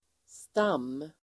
Uttal: [stam:]